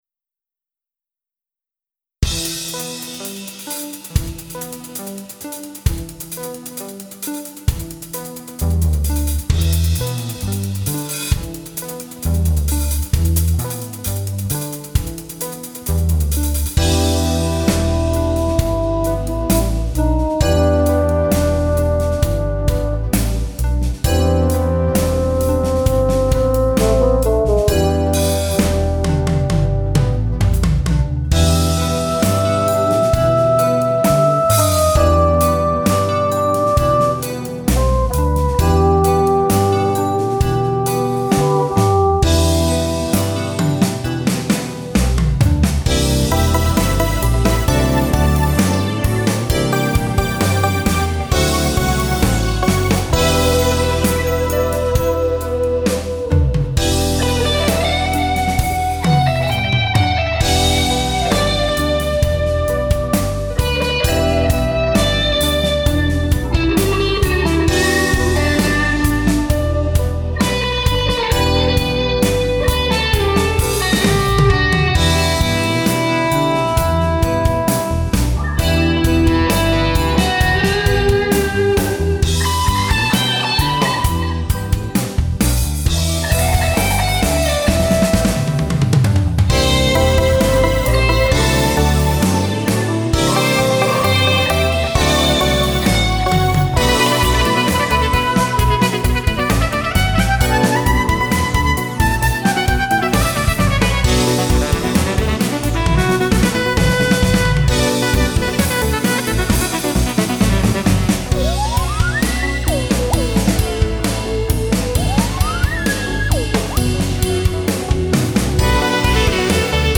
JAZZ ROCK / FUSION / GROOVE